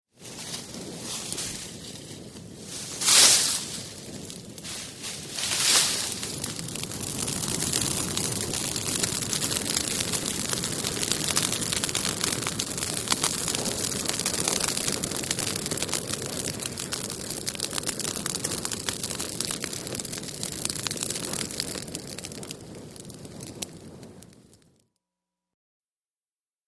FireRoarsCrackles PE700501
FIRE CAMP FIRE: EXT: Throwing brush on a fire, fire roars & crackles, audible fire convection.. Fire Burn.